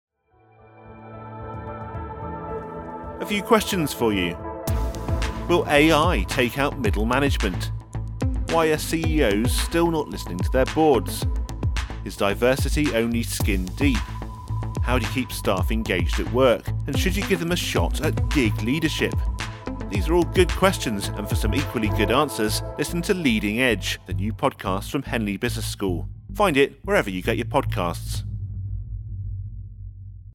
We sponsored a package of presenter led audio teasers across both Acast and Spotify News & Politics podcasts.